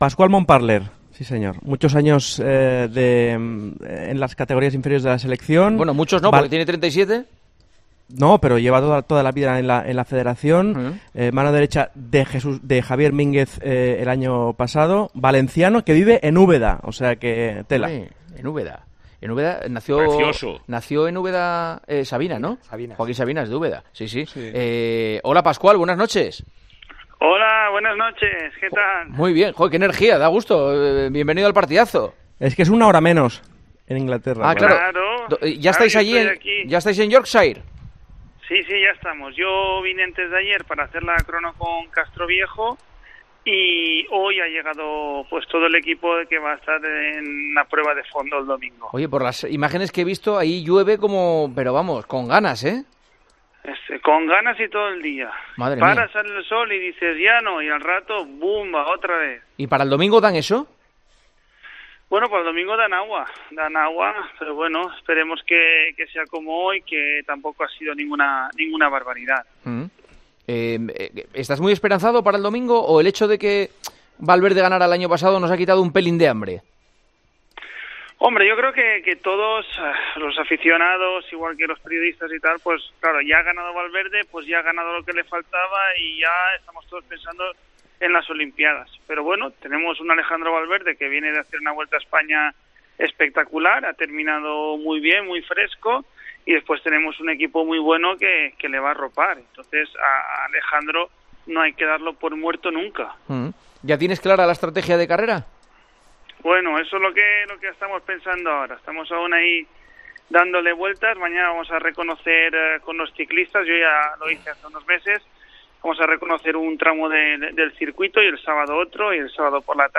charló con Juanma Castaño este jueves en El Partidazo de COPE.